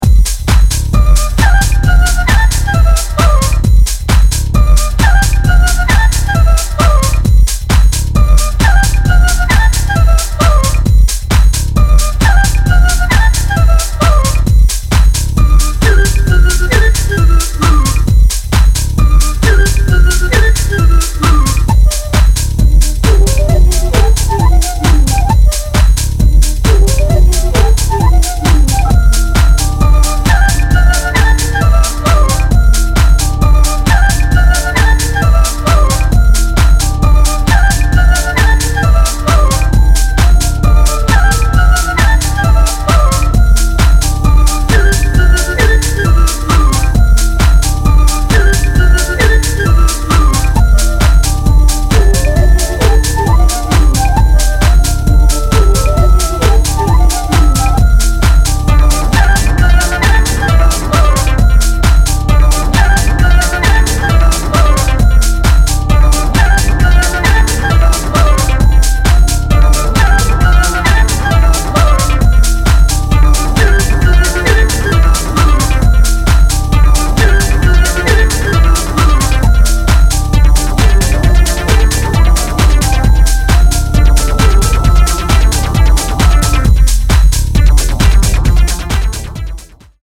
尺八ような音色とアシッドをフックにBPM134で爆走するB-1